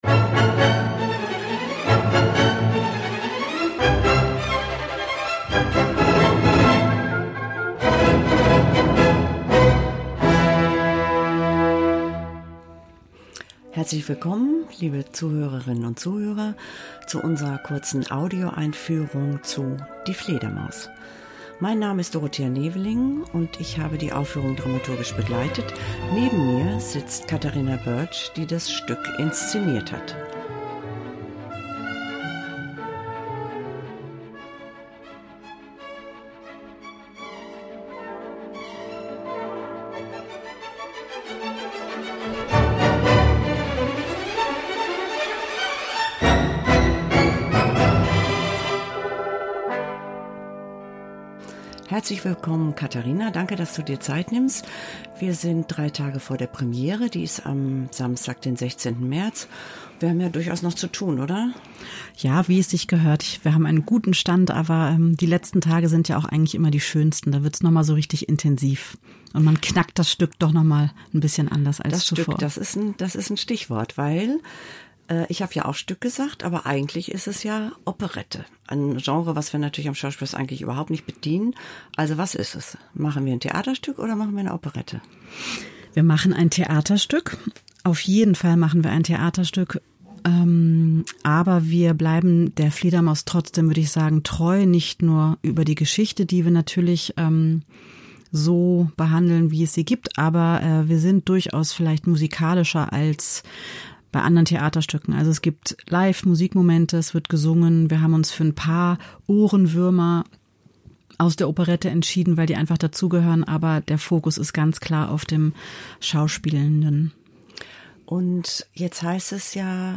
Erfahren Sie mehr über neue Inszenierungen aus dem Schauspielhaus
Künstler*innen und Dramaturg*innen der Produktion.